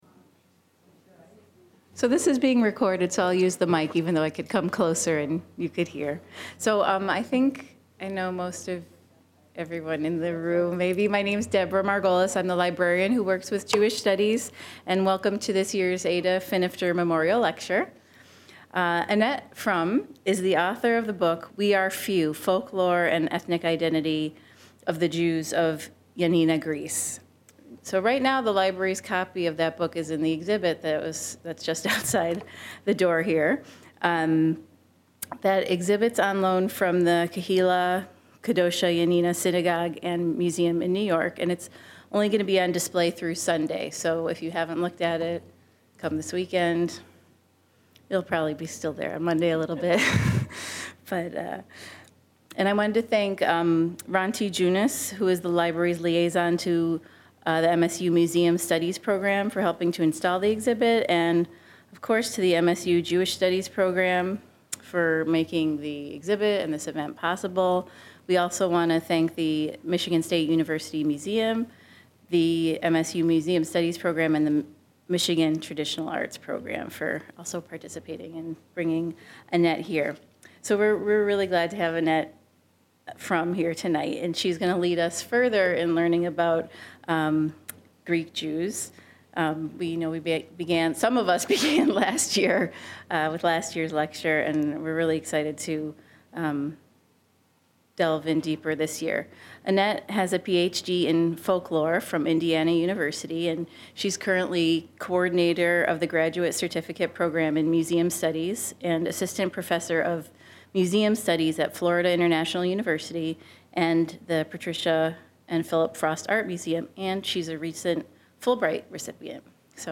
and how the culture and practices have been preserved even as the Jews emigrated to the U.S. She discusses on weddings and other rituals which have survived in the New World and describes her family connections. Question and answer concludes the session.